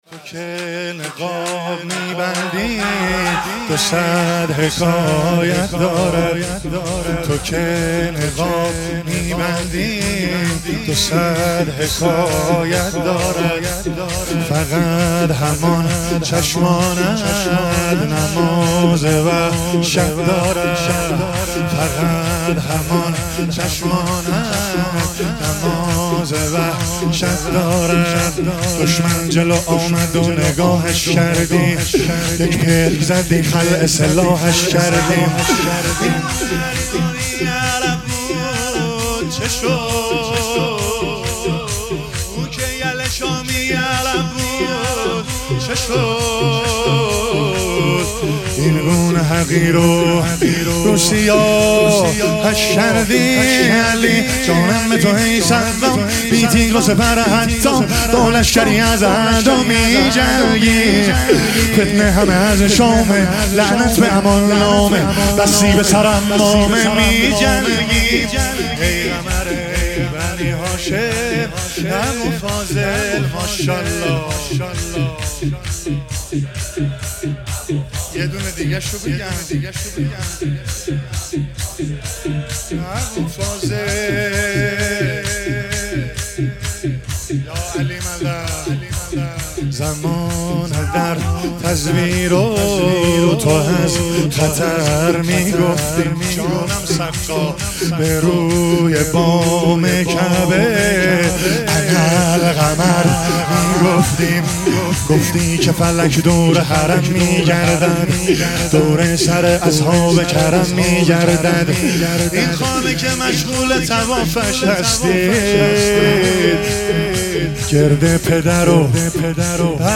شور
شهادت حضرت زهرا سلام الله علیها 1443 (ه ق)